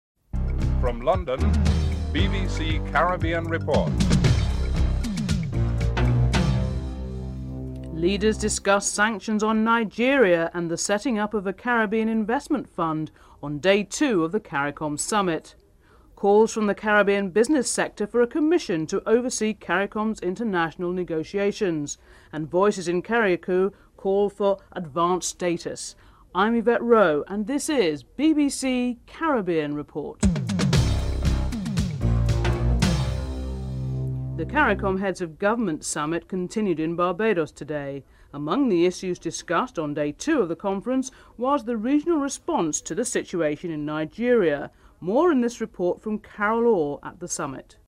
1. Headlines (00:00-00:33)
Prime Minister James Mitchell is interviewed (11:38-13:05)